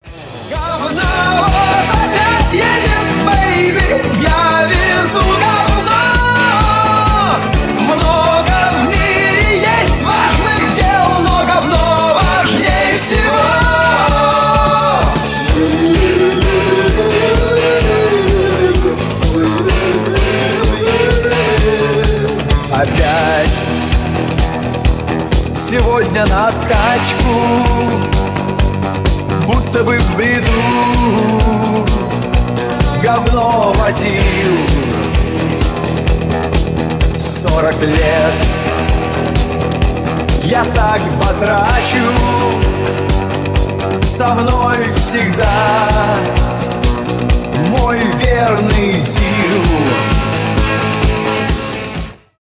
mashup , glam rock